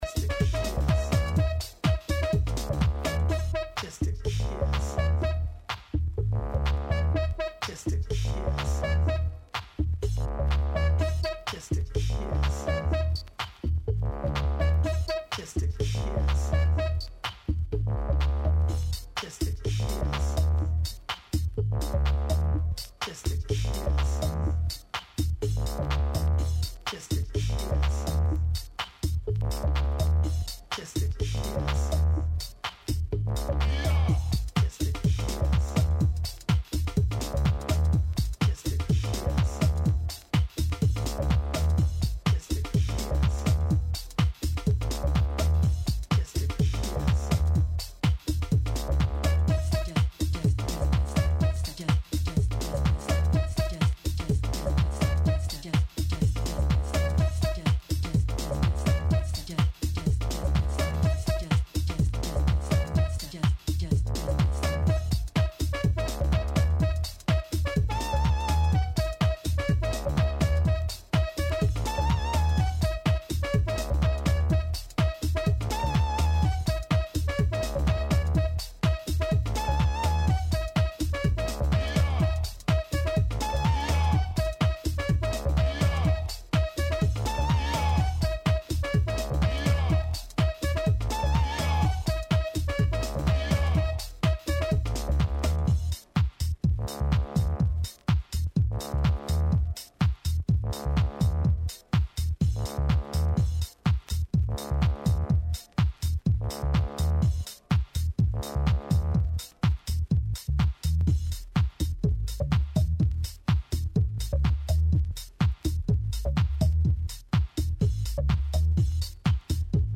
special interview zikenntsock.mp3